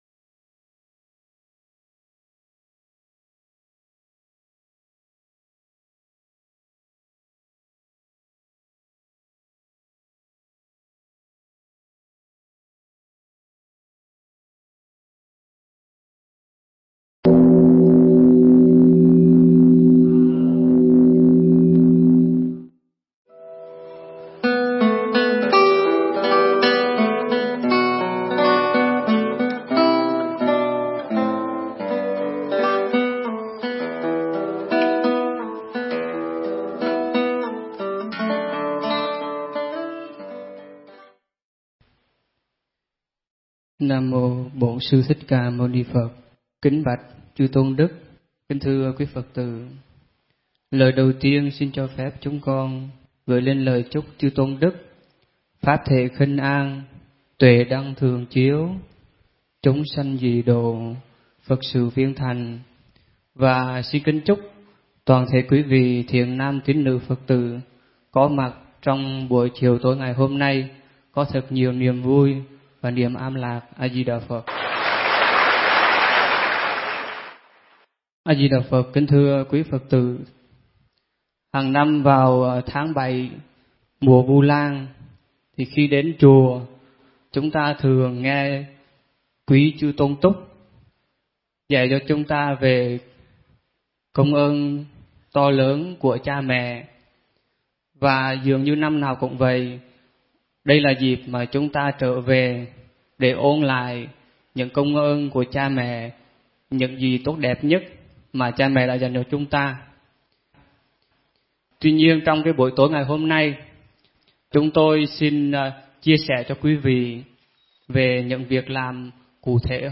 Mp3 Thuyết Pháp Làm thế nào để báo hiếu mẹ cha